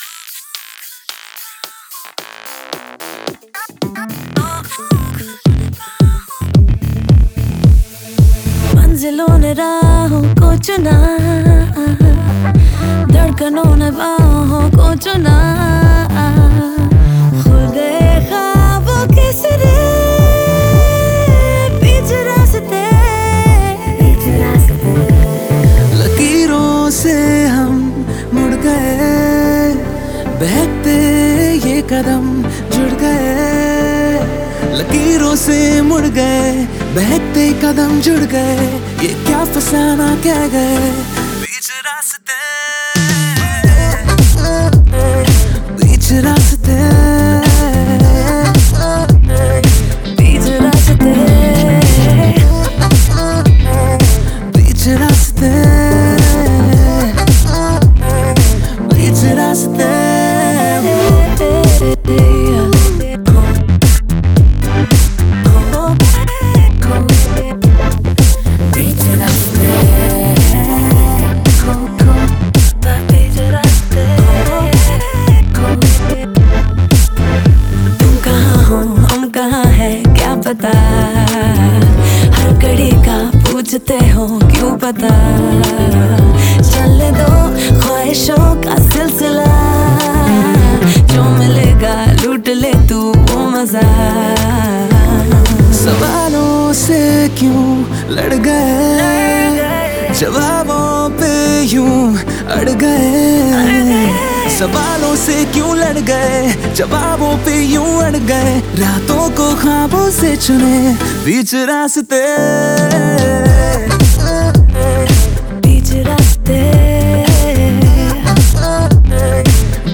Indian POP Mp3 Song